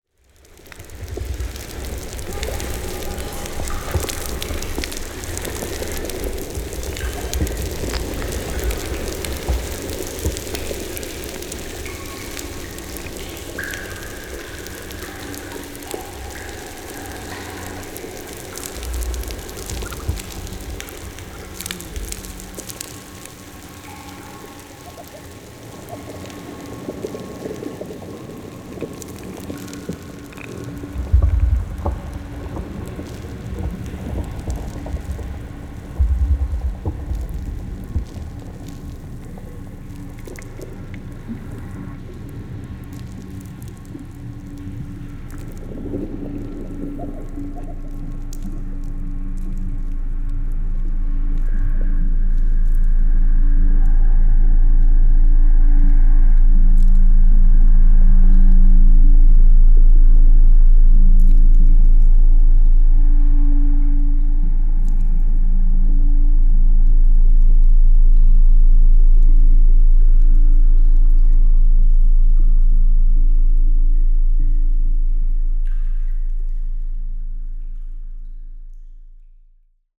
Sound installation
12.1 audio format, 9’12” continuous loop